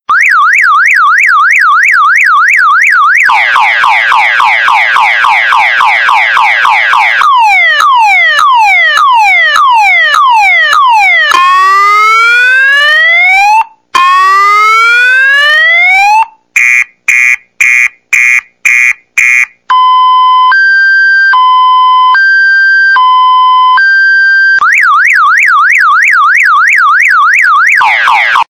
Car-Alarm
Car-Alarm.mp3